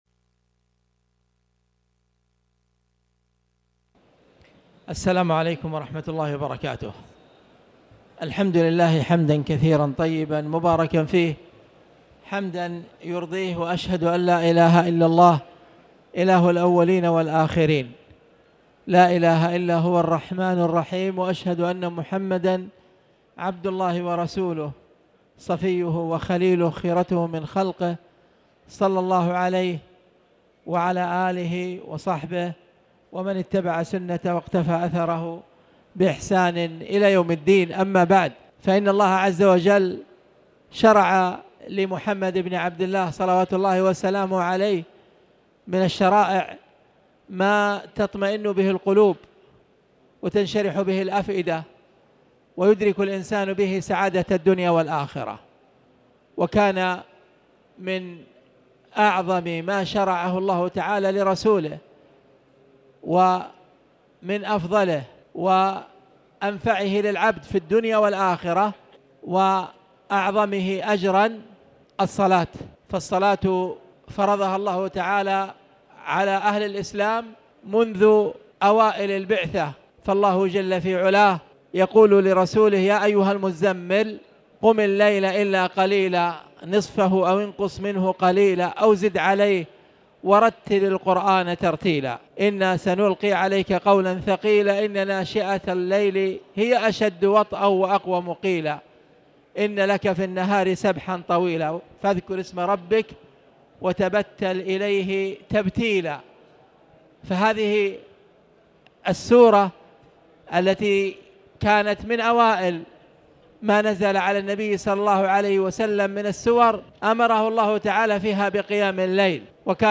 تاريخ النشر ١٤ جمادى الأولى ١٤٣٩ هـ المكان: المسجد الحرام الشيخ